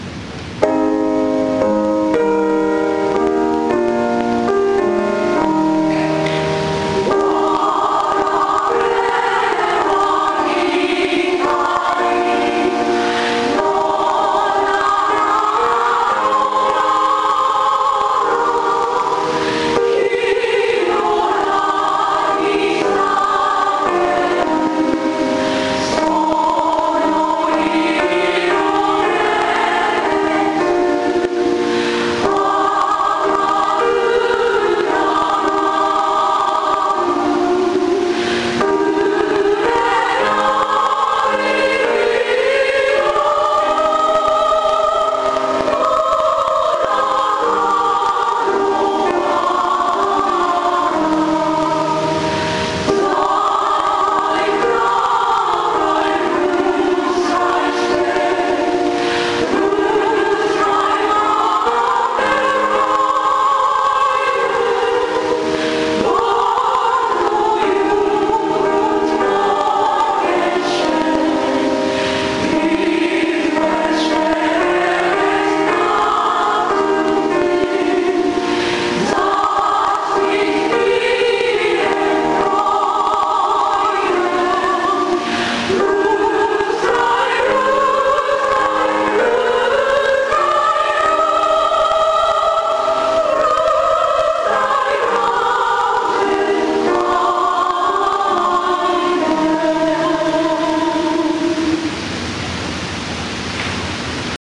坂越地区婦人会コーラスだったのですが、
平成15年7月 フェスタ・アルモニカ・イン・アコウ
音悪いです、おまけに重いです（泣）